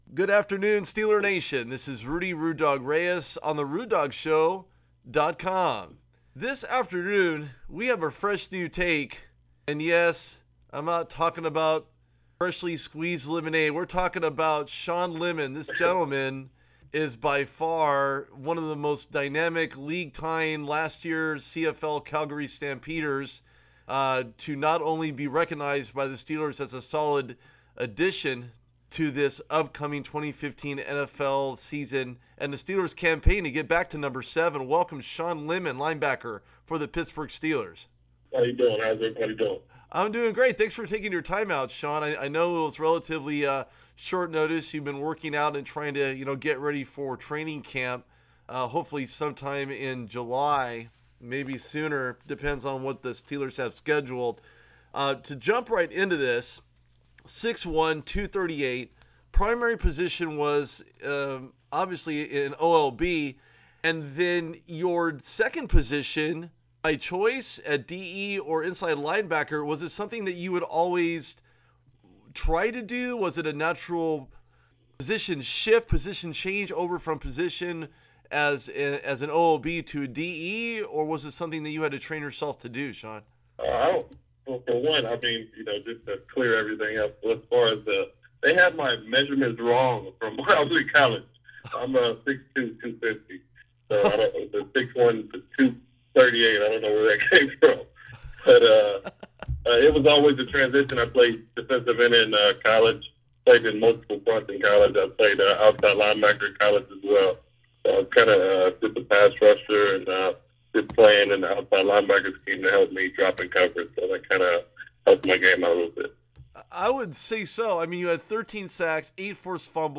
Pittsburgh Steelers Player Interviews